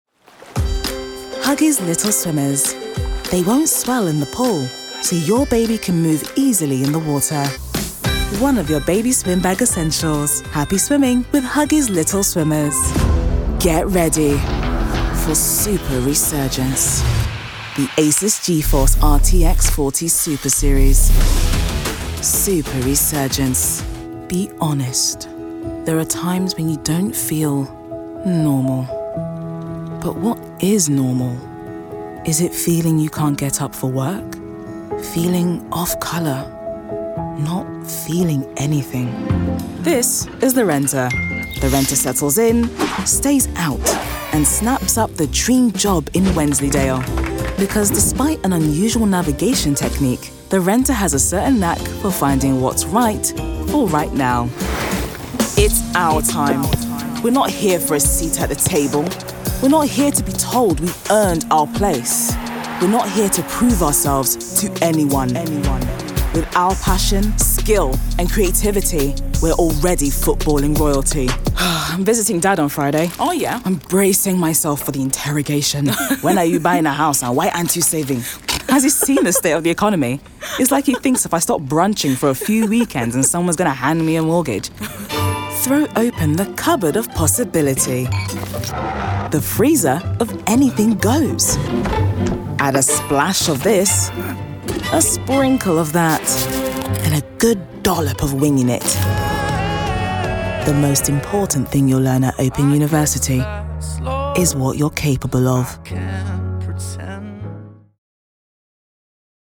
The Smooth and Calm, with Resonance.
Commercial Reel 2024
London, Multicultral Language English, RP
With a voice that is not only resonant, I believe I am versatile in range and can adapt to requests which includes warmth, a touch of earthiness, alongside being smooth and calm but can be assertive, grounded and trustworthy too.